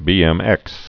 (bēĕmĕks)